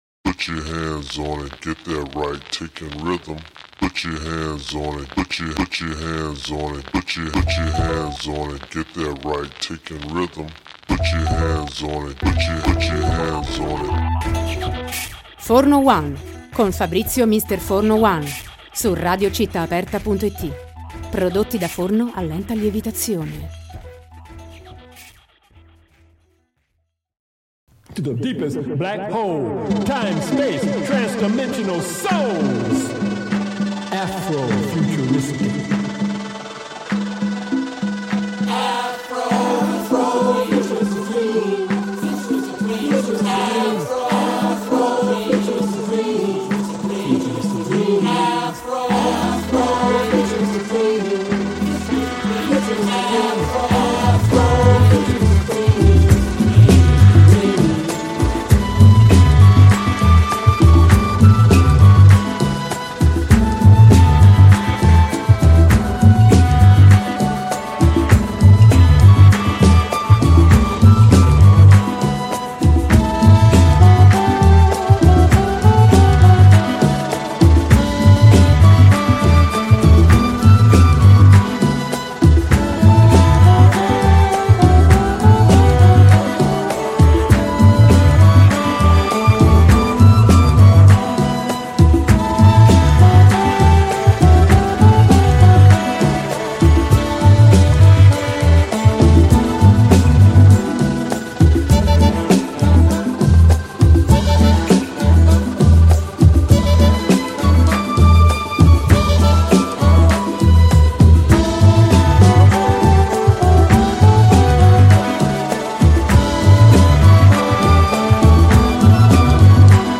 L’intervista è completata dall’ascolto di brani che ben rappresentano l’aspetto musicale di questo fenomeno, che abbraccia anche letteratura, cinema e pittura.